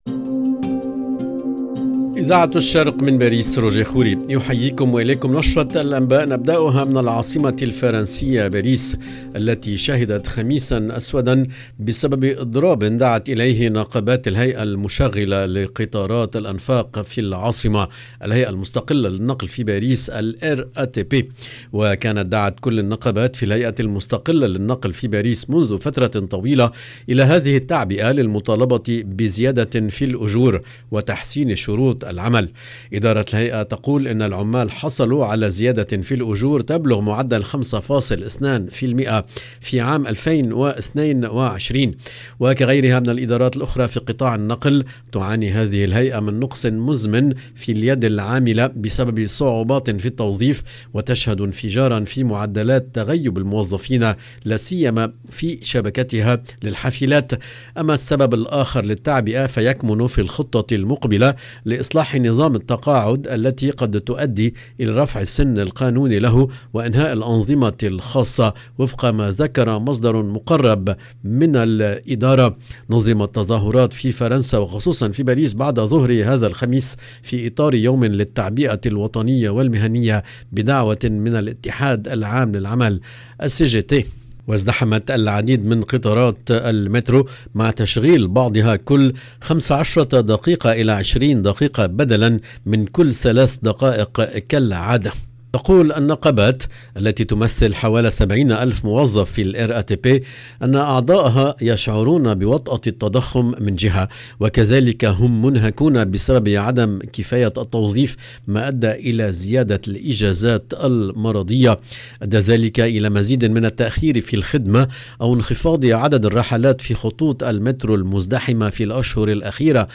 LE JOURNAL EN LANGUE ARABE DU SOIR DU 10/11/22